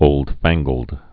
(ōldfănggəld)